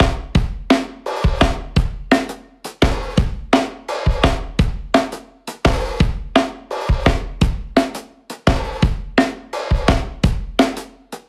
60s Lo-Fi（DB-30 OFF / ON）
60s-Lo-Fi Snare-WET[658].mp3